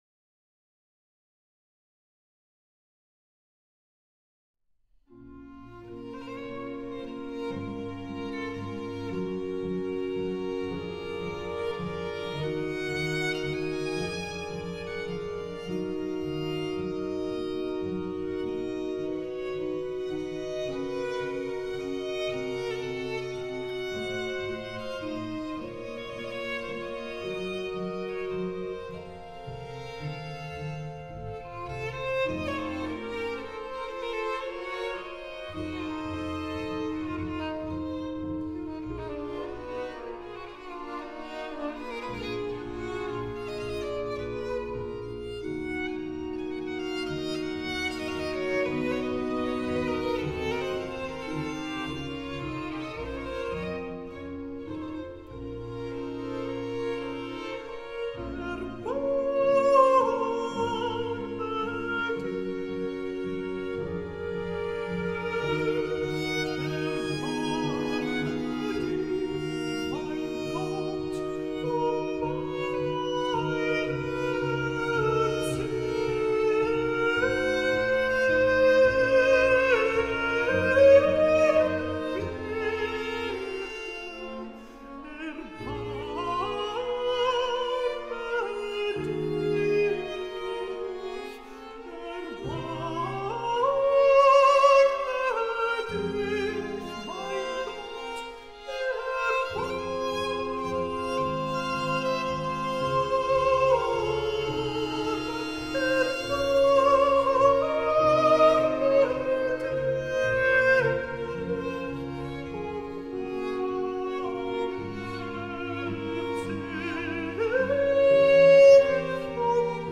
Aria